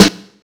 Snare (69).wav